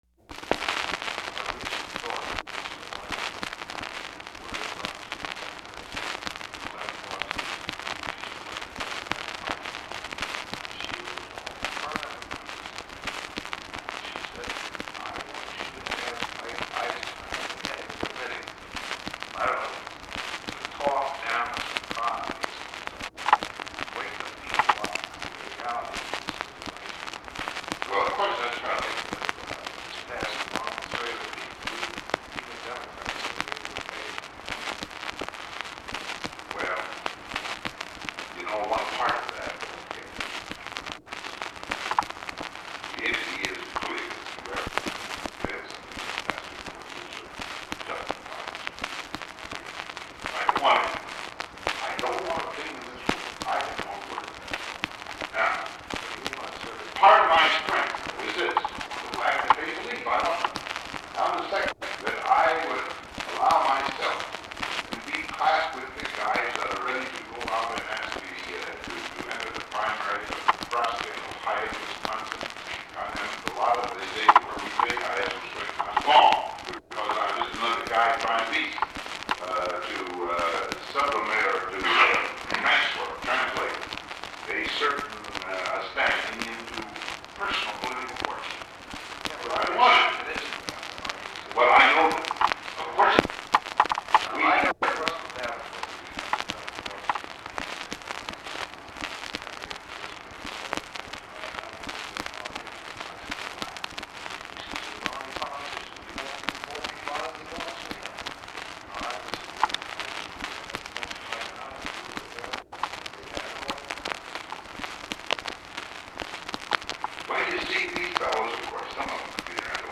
The recording contains three separate conversations .
Secret White House Tapes